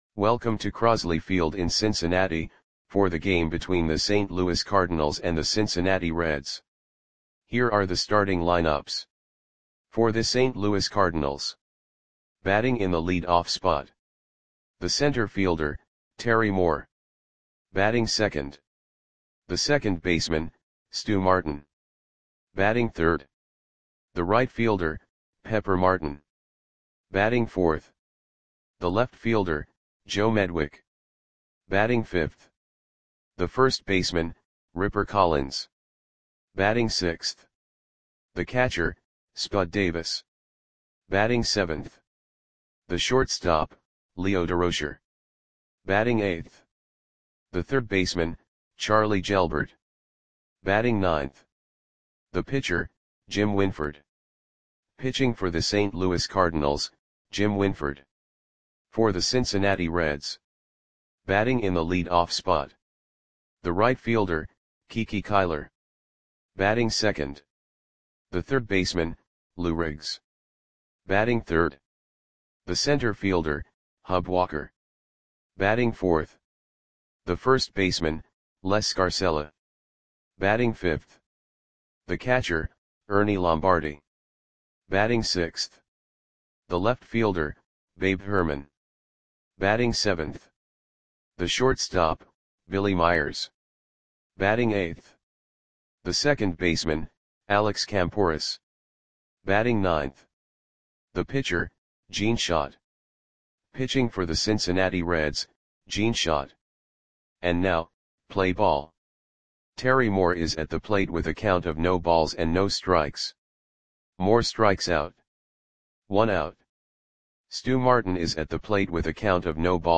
Audio Play-by-Play for Cincinnati Reds on July 4, 1936